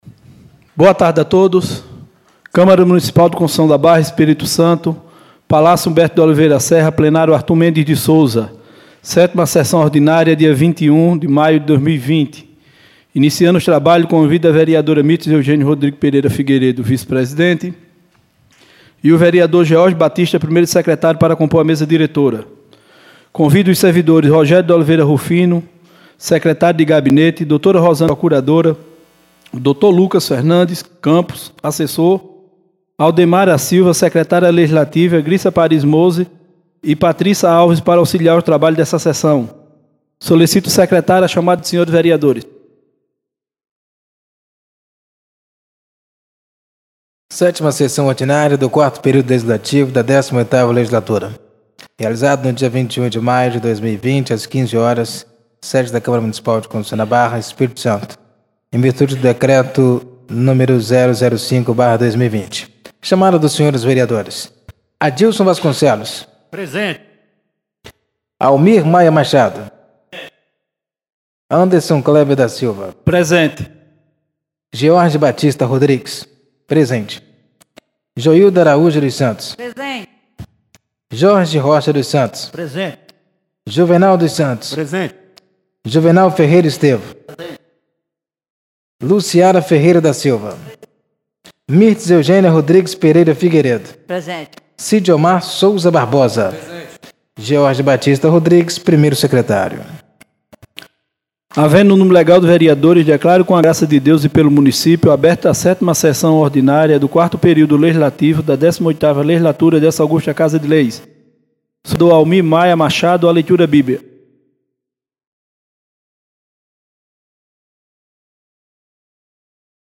7ª Sessão Ordinária do dia 21 de maio de 2020